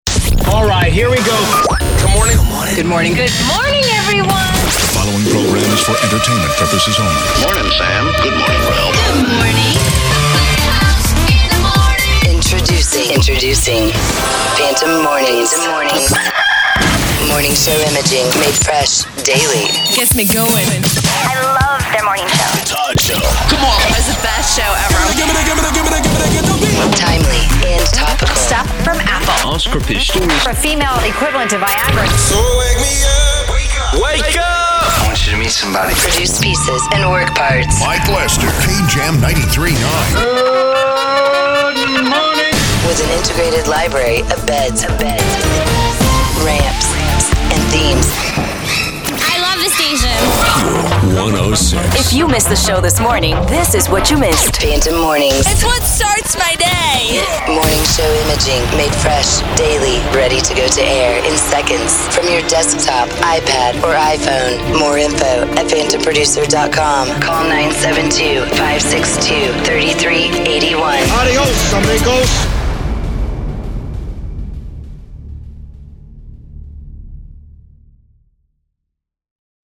Phantom Mornings is the new production imaging service exclusively catering to morning shows. Topical and timely imaging made fresh daily - customized instantly via the Phantom App - and on the air in seconds from your desktop, iPad or iPhone.